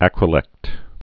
(ăkrə-lĕkt)